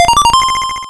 RedCoin4.wav